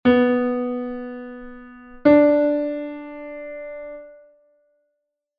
Listening to ascending and descending intervals